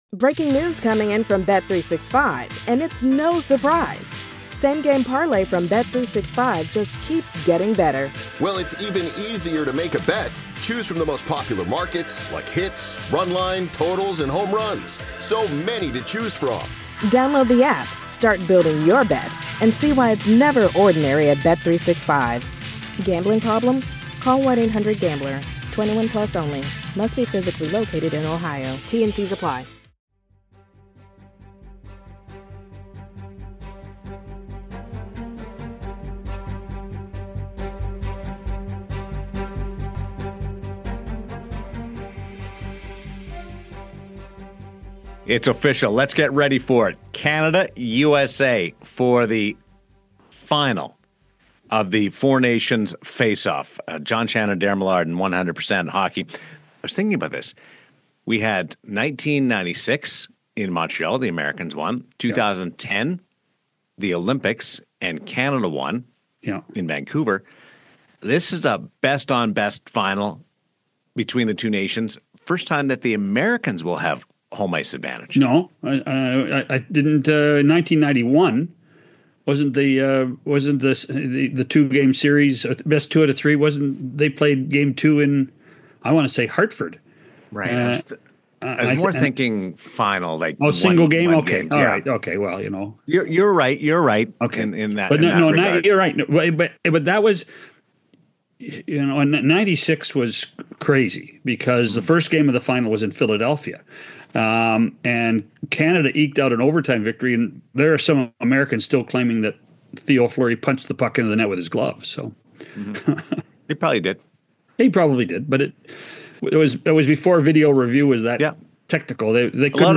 Following Canada’s win over Finland, Rick Bowness joins the show to break down the matchup and set the stage for a highly anticipated rematch against the U.S. in the 4 Nations Face-Off.